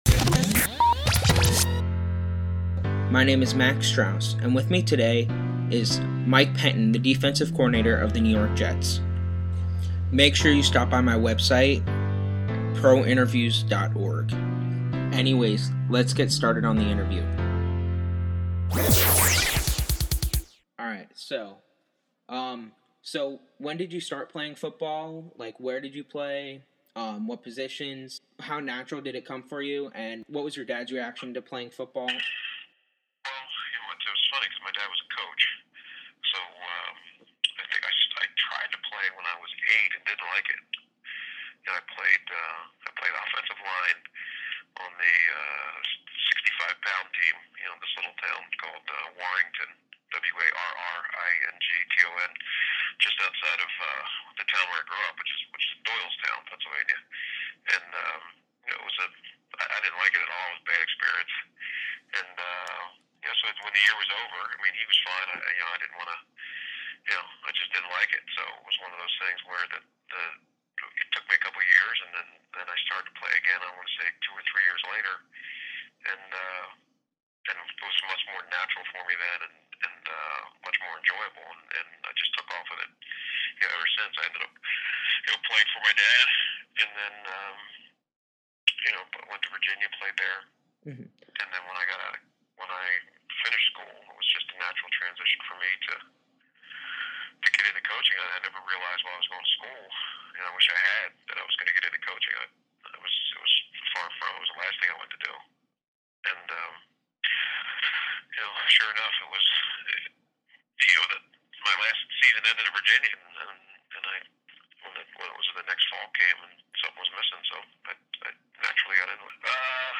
Jets Defensive Coordinator, Mike Pettine Interview